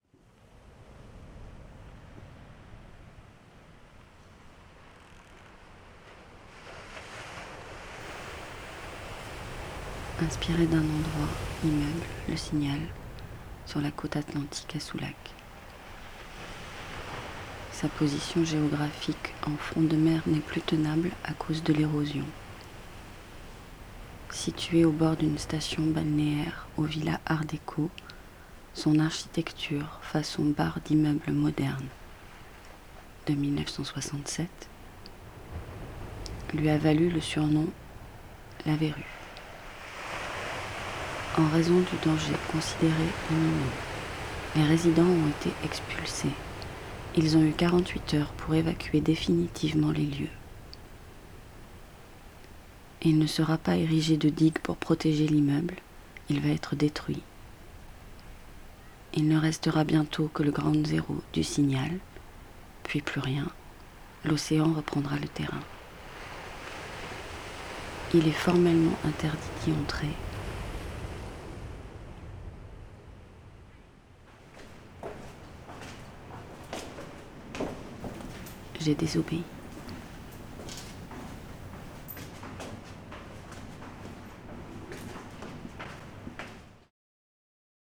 intro46foisversionbruitvaguesescalier.wav